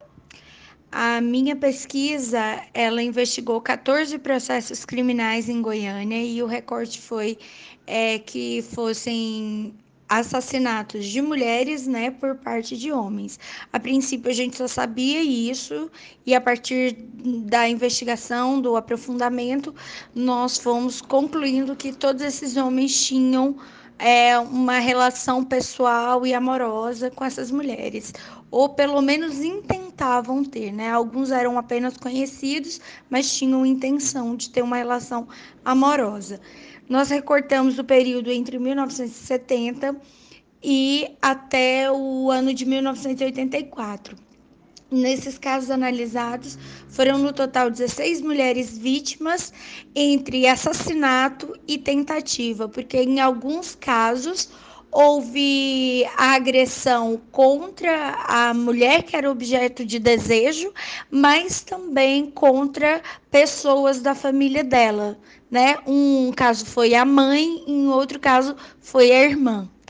Historiadora